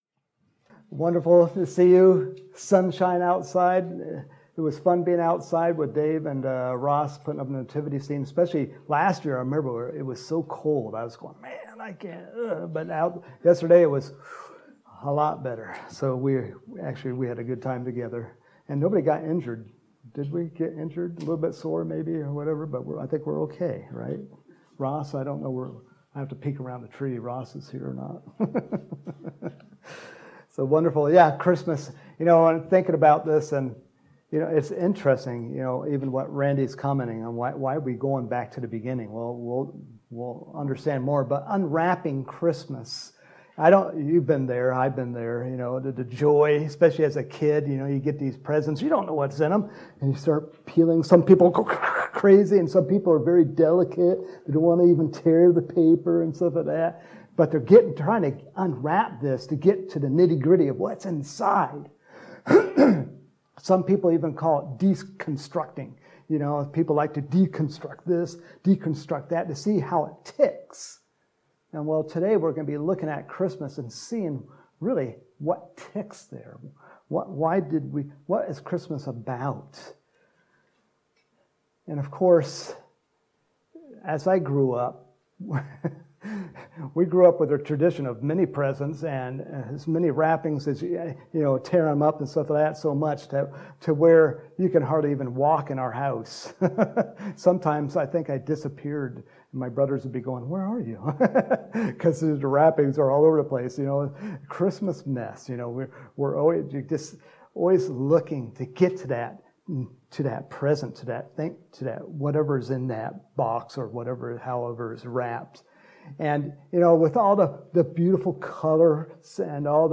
Home › Sermons › December 5, 2021